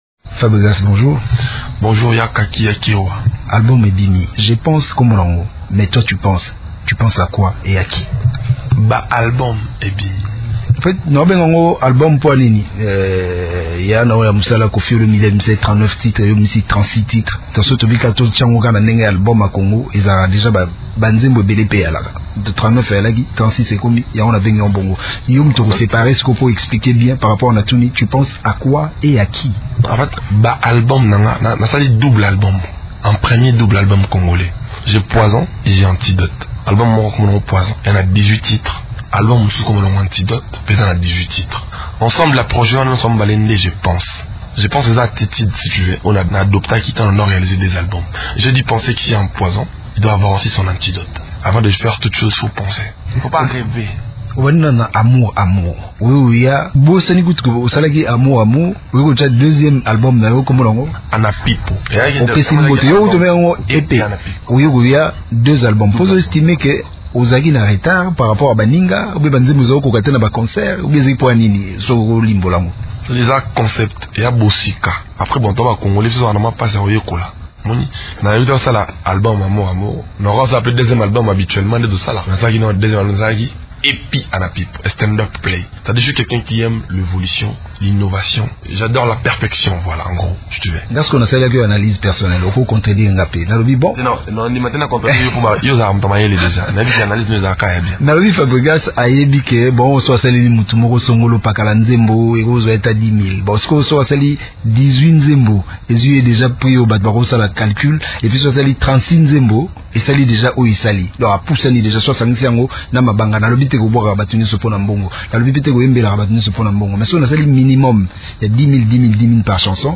L’artiste-musicien congolais Fabregas, alias Métis noir, a sorti, depuis quelques jours, l’album intitulé: «Je pense» sur le marché de disques. Il l’a annoncé dans un entretien accordé, jeudi 31 mars, à Radio Okapi.